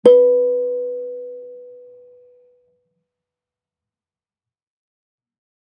kalimba1_circleskin-B3-ff.wav